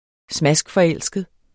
Udtale [ ˈsmasgˈfʌˈεlˀsgəð ]